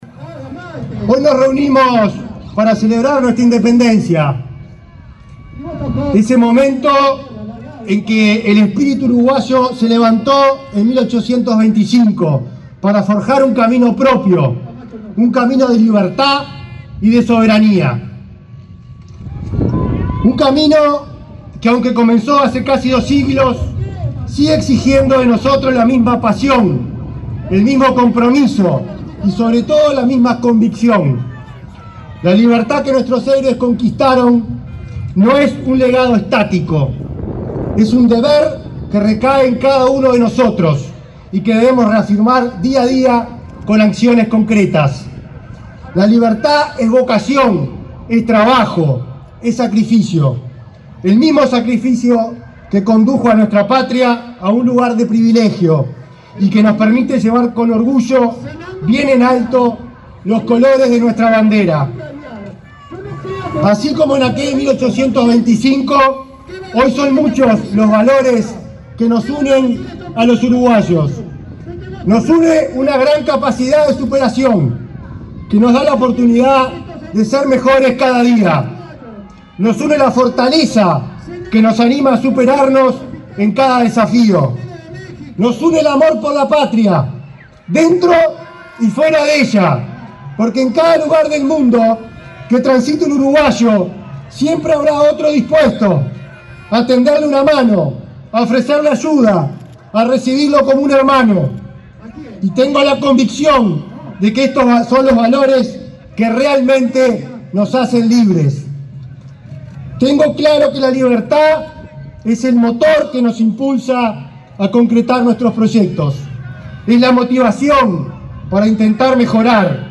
Palabras del ministro del Interior, Nicolás Martinelli 25/08/2024 Compartir Facebook X Copiar enlace WhatsApp LinkedIn El ministro del Interior, Nicolás Martinelli, fue el orador central por el Poder Ejecutivo en el acto conmemorativo del 199.° aniversario de la Declaratoria de la Independencia, realizado este domingo 25 en el departamento de Florida.